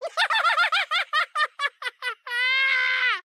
*大笑*